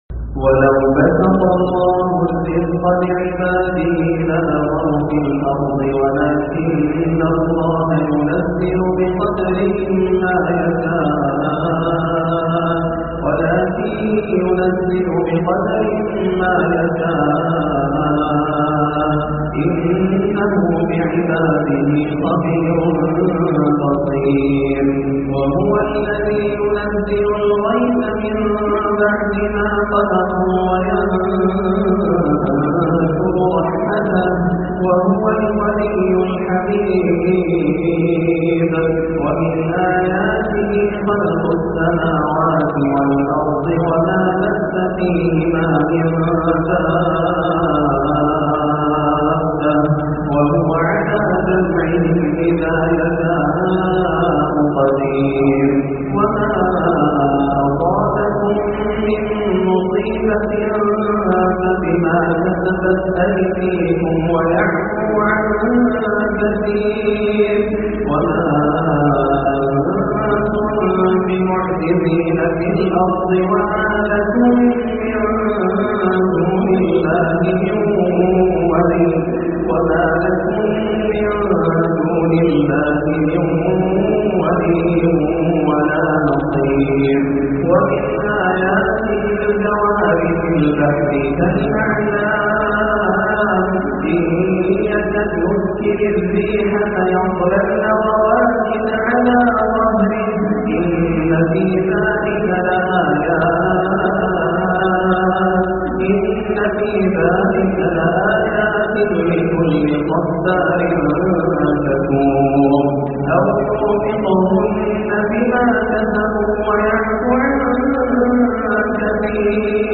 من أواخر سورة الشورى - عشاء 3-6-1430 > عام 1430 > الفروض - تلاوات ياسر الدوسري